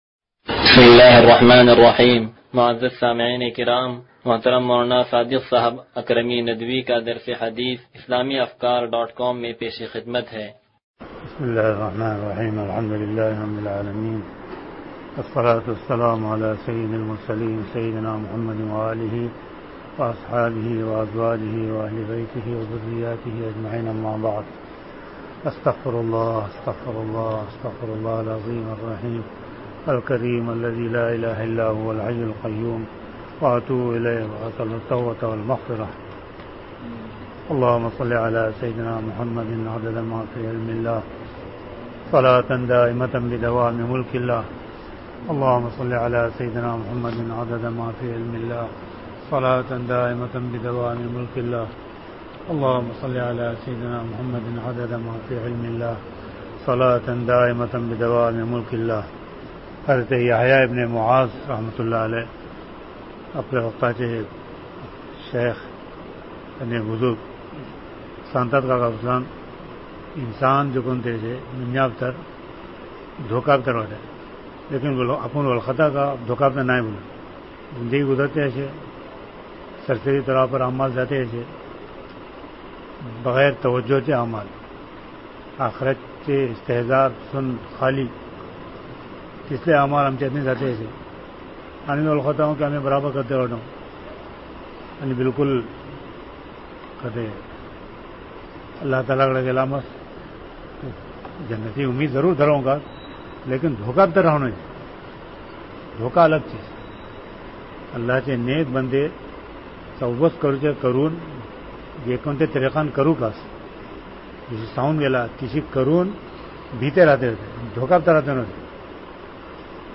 درس حدیث نمبر 0175